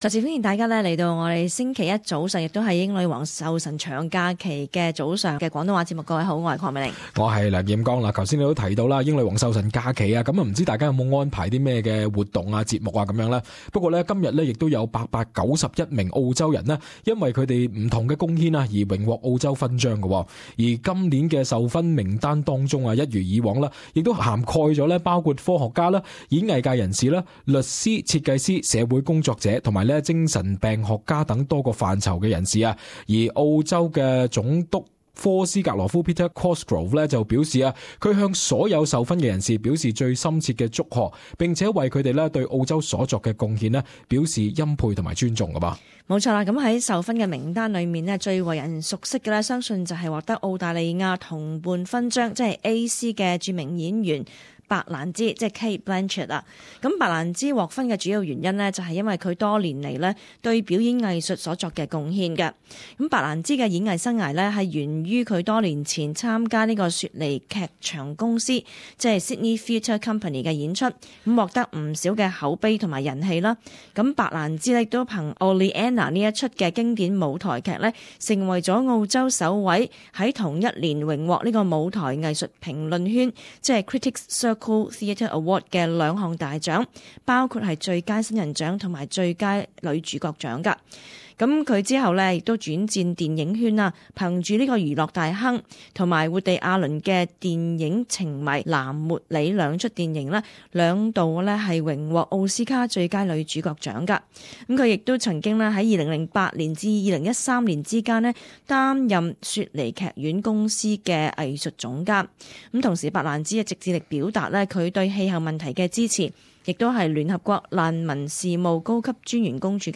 【時事報導】英女皇壽辰近九百澳洲人授勳